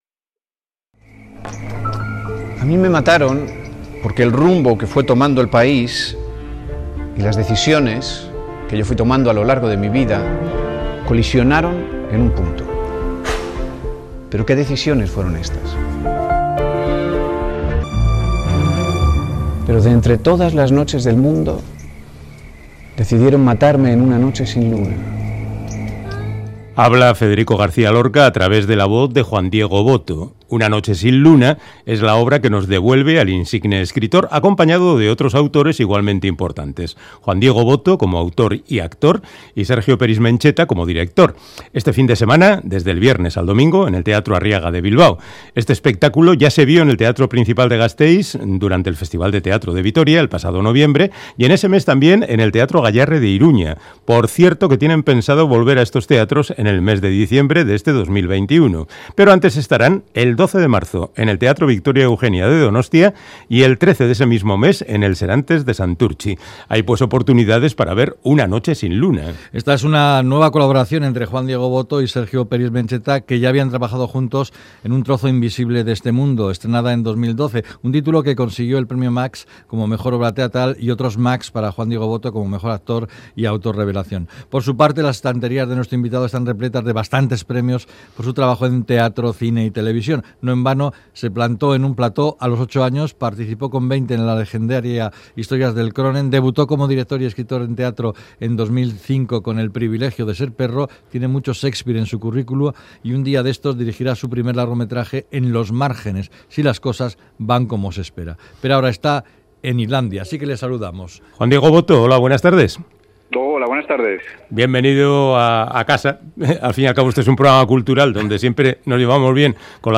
Audio: Charlamos con el actor Juan Diego Botto que vuelve a Euskadi para representar "Una noche sin luna", obra en la que interpreta a Federico García Lorca